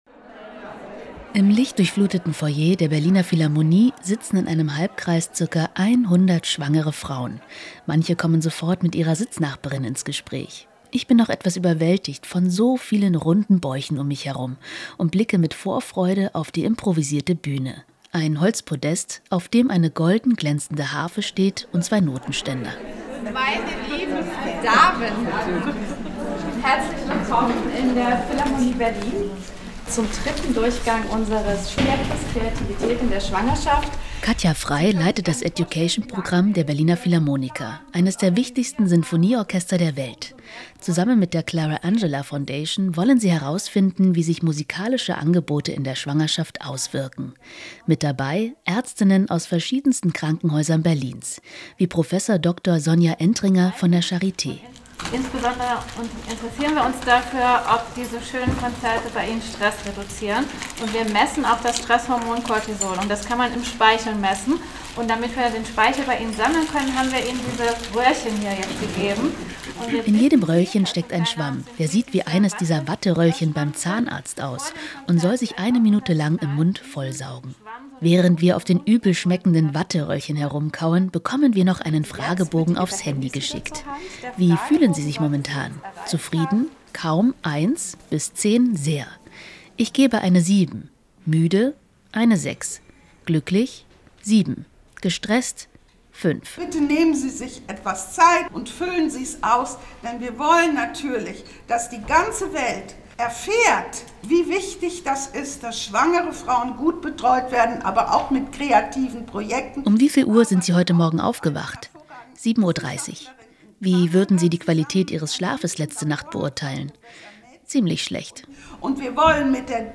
Die Reportage aus Berlin und Brandenburg - Mit Musik zu weniger Stress in der Schwangerschaft?